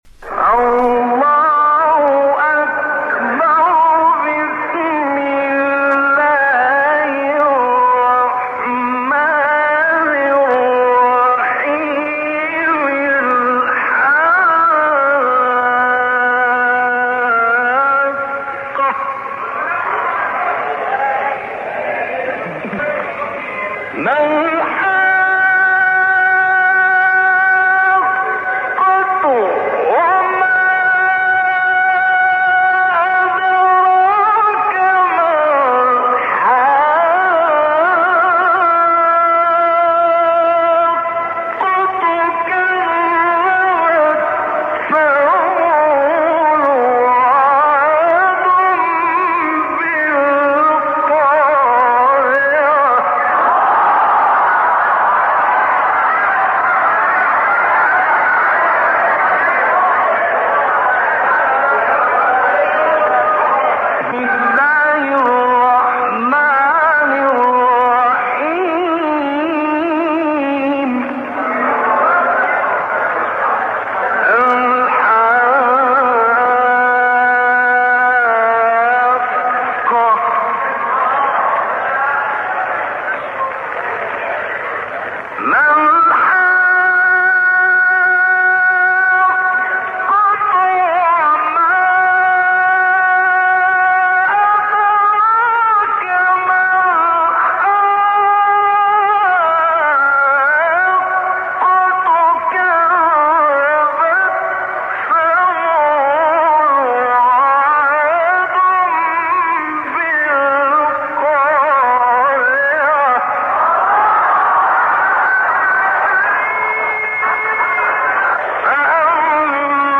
گروه فعالیت‌های قرآنی ــ راغب مصطفی غلوش با این‌که صوت فاخری داشته و یک جلال و وقار با طنین زیبا در صدای وی وجود دارد، اما وی سعی در ادای فصیح کلمات و رعایت احکام تجویدی دارد.